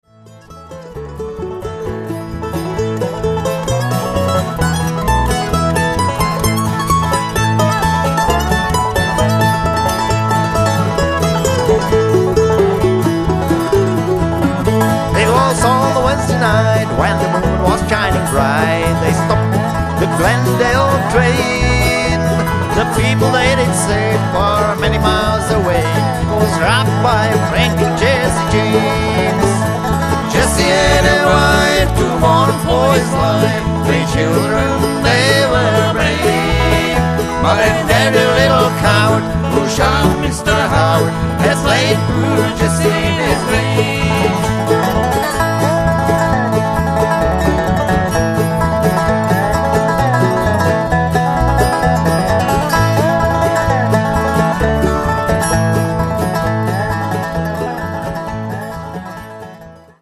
Guitar
Banjo
Mandolin
Dobro
Electric Bass